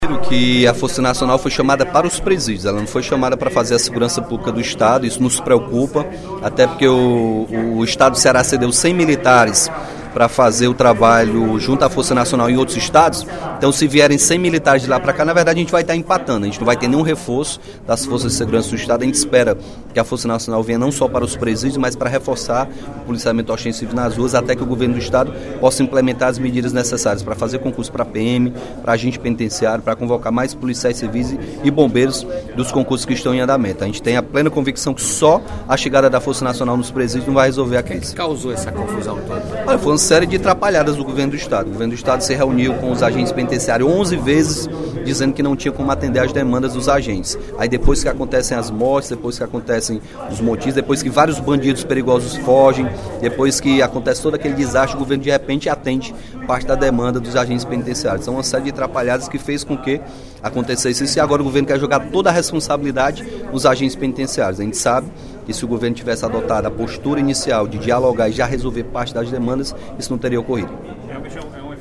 O deputado Capitão Wagner (PR) comentou, durante o primeiro expediente da sessão plenária desta terça-feira (24/05), os motins realizados nas unidades prisionais de Itaitinga e Caucaia, no último final de semana. De acordo com o parlamentar, o Estado erra ao querer delegar a culpa das rebeliões aos agentes penitenciários.